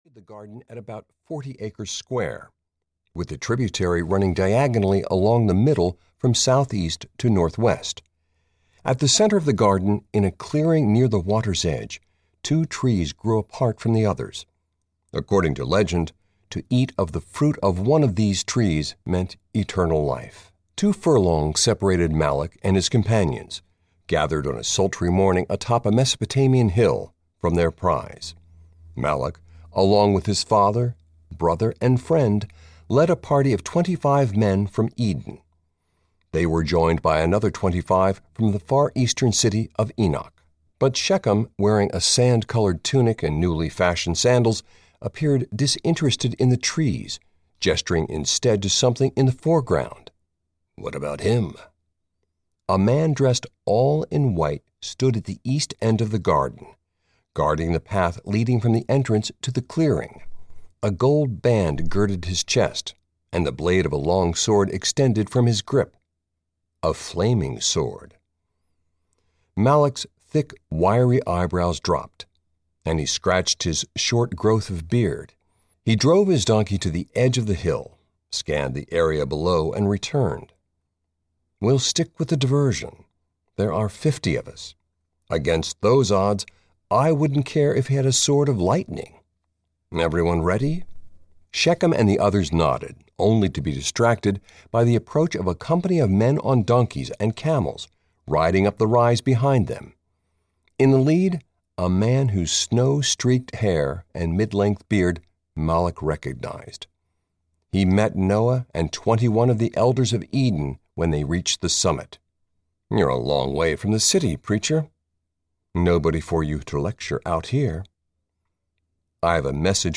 Army of God (EN) audiokniha
Ukázka z knihy